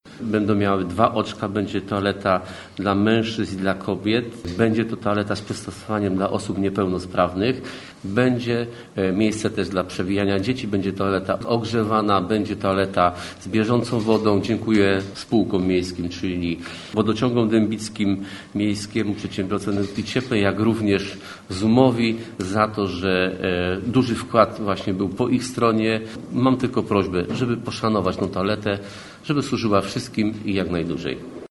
Mówi Mariusz Szewczyk burmistrz Dębicy.